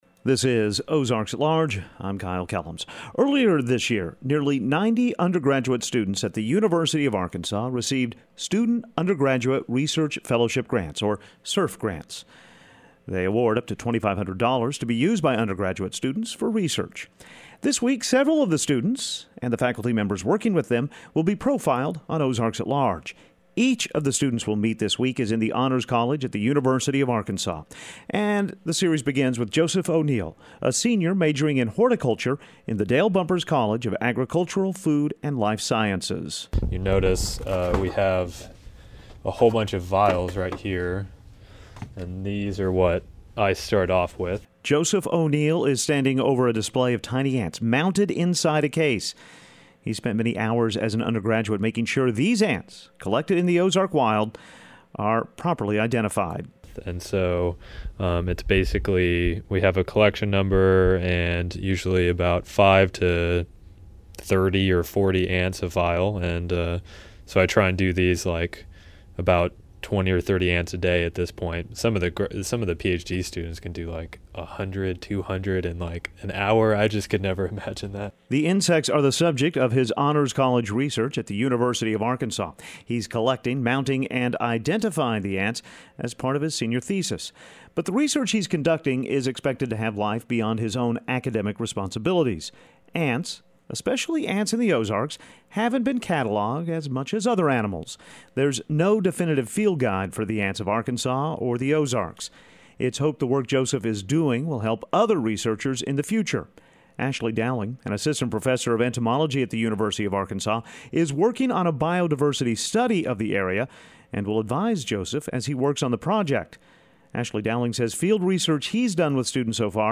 His is the first in an Ozark at Large series of interviews with U of A Honor College students and their faculty mentors regarding the research work they've undertaken.